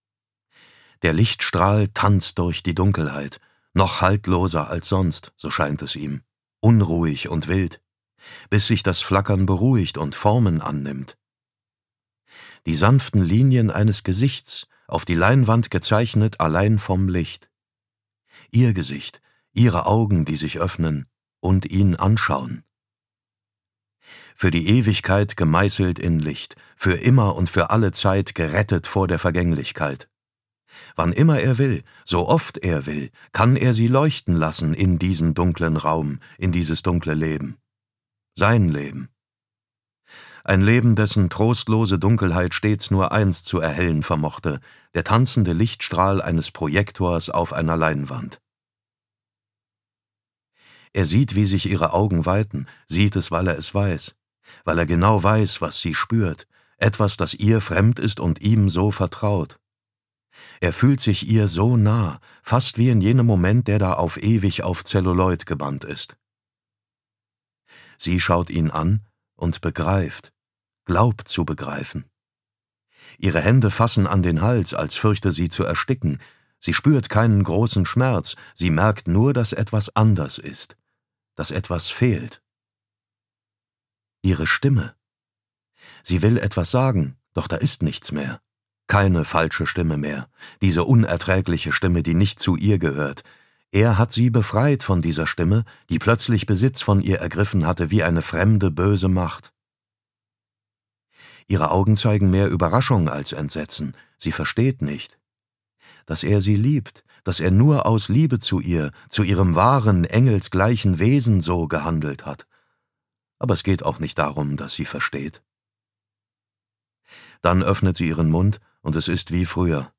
Der stumme Tod (DE) audiokniha
Ukázka z knihy
• InterpretDavid Nathan